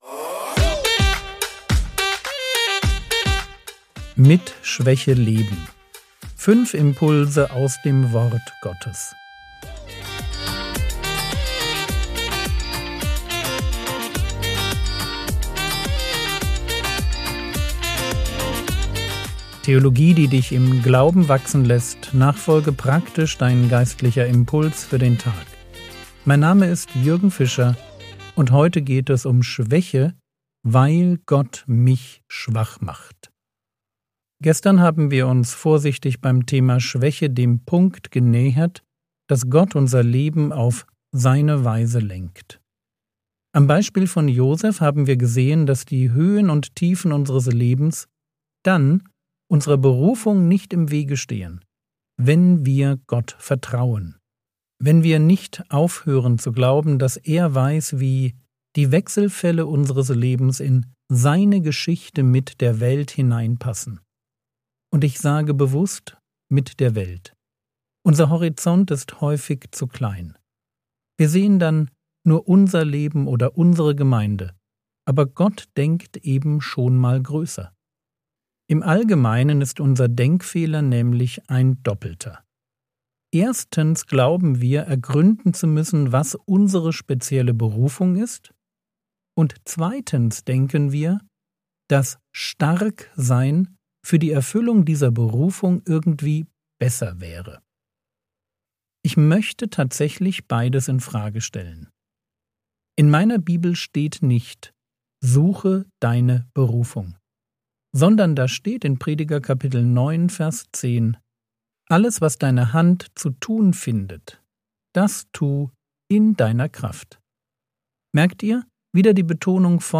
Mit Schwäche leben (3/5) ~ Frogwords Mini-Predigt Podcast